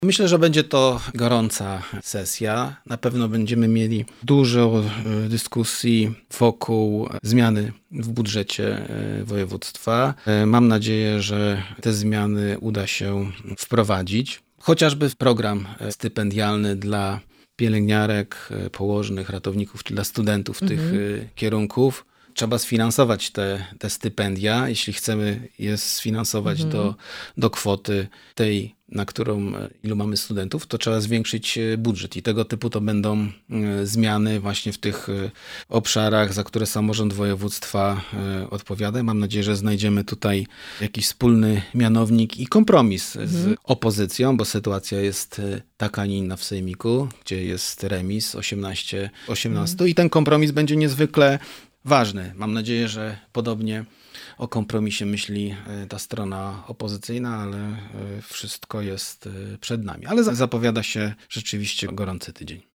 -To będzie gorąca sesja, mówi wicemarszałek Marcin Krzyżanowski.